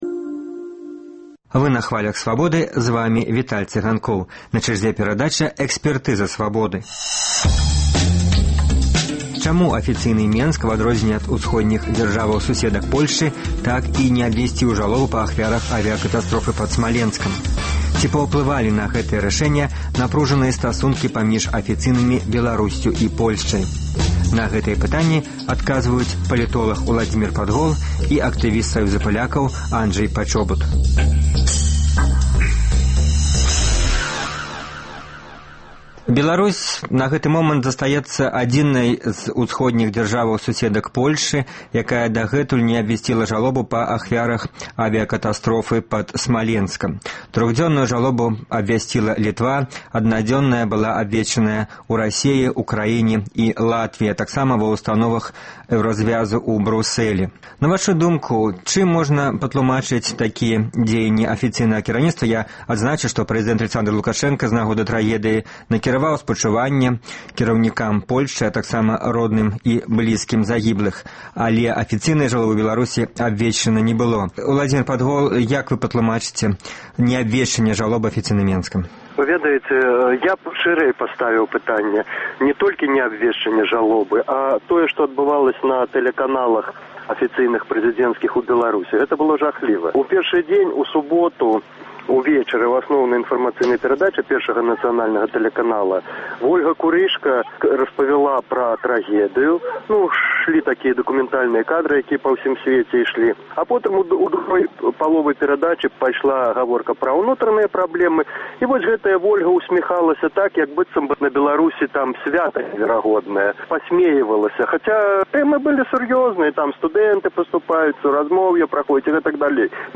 Ці паўплывалі на гэтае рашэньне напружаныя стасункі паміж афіцыйнымі Беларусьсю і Польшчай? На гэтыя пытаньні адказваюць палітоляг, кандыдат філязофскіх навук